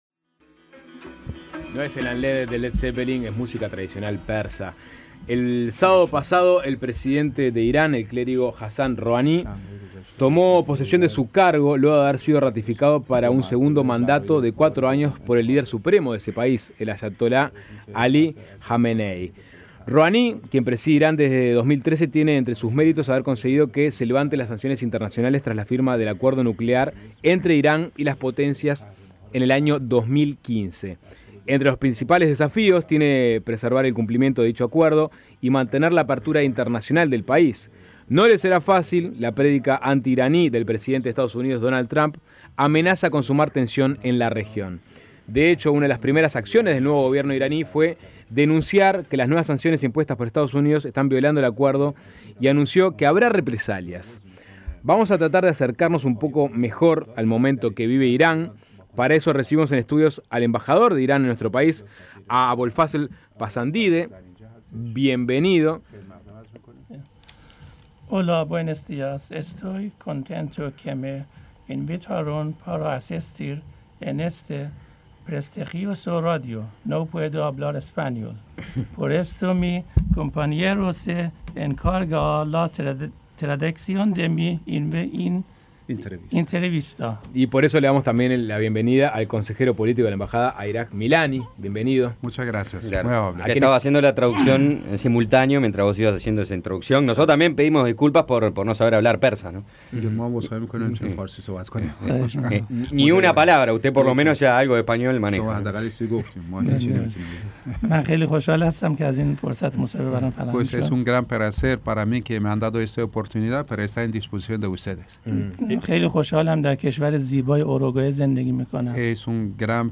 En conversación con Suena Tremendo, el embajador de Irán en Montevideo, Abolfazl Pasandideh, opinó que Estados Unidos tiene un doble estandarte para evaluar a los gobiernos de la región.